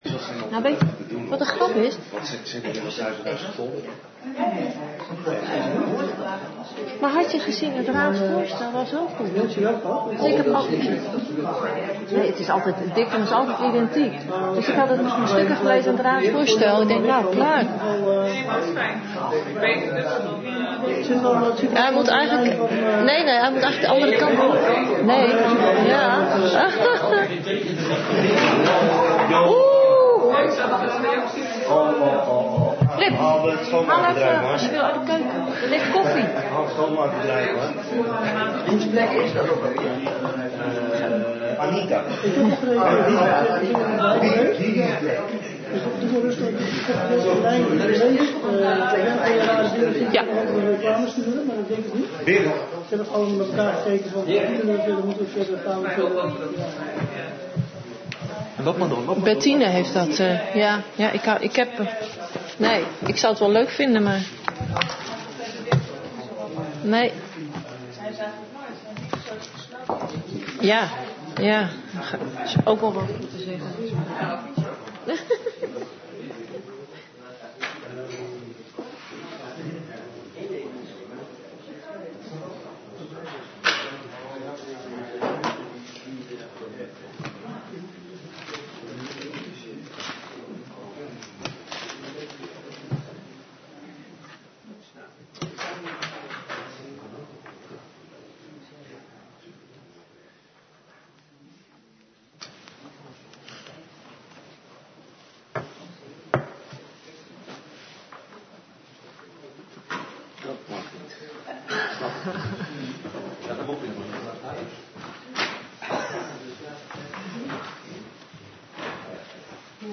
De vergadering wordt gehouden in de Raadzaal met in achtneming van de 1,5 meter regel.